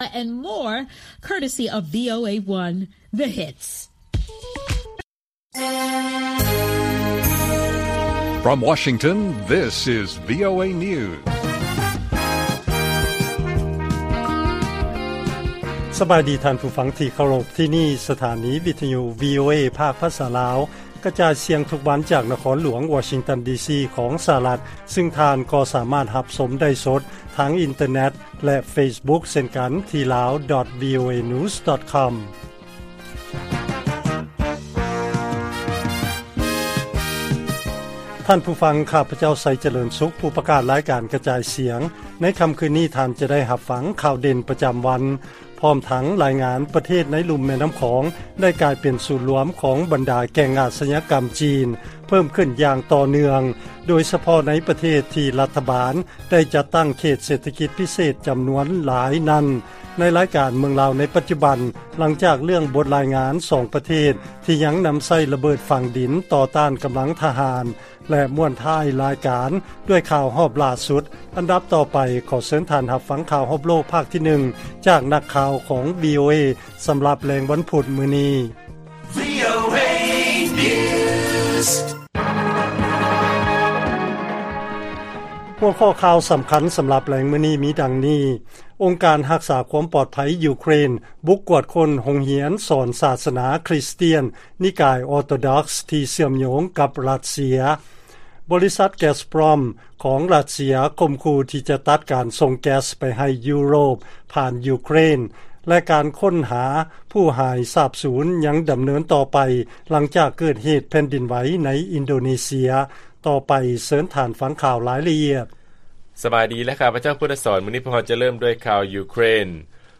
ລາຍການກະຈາຍສຽງຂອງວີໂອເອ ລາວ: ອົງການຮັກສາຄວາມປອດໄພ ຢູເຄຣນ ບຸກກວດຄົ້ນໂຮງຮຽນສອນສາສະໜາຄຣິສຕຽນ ນິກາຍອໍໂຕດັອກສ໌